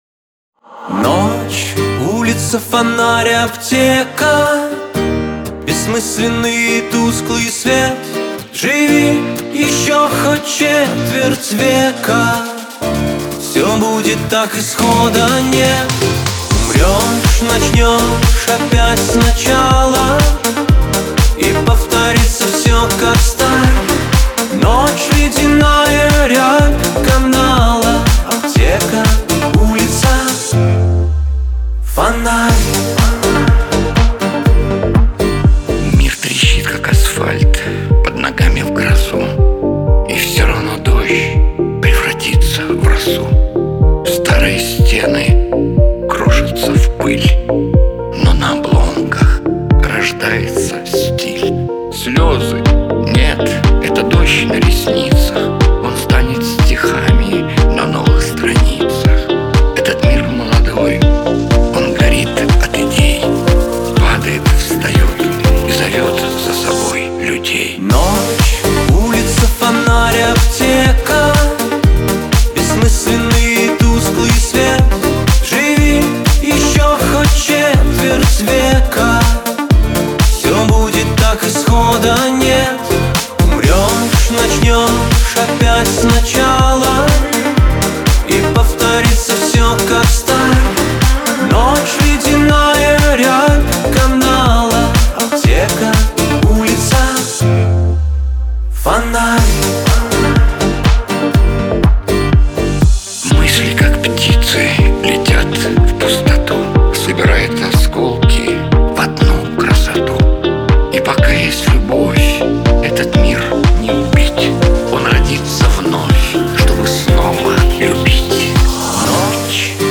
грусть
Шансон , Лирика , дуэт